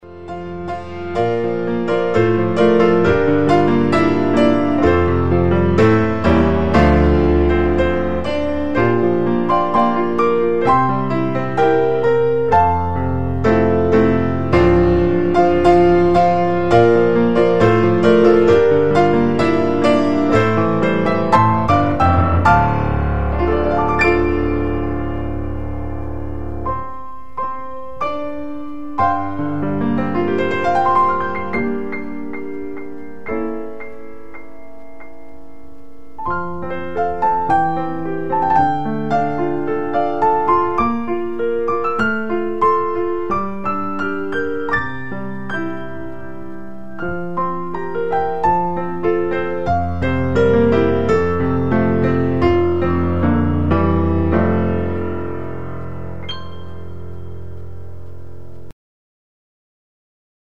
Piano CDs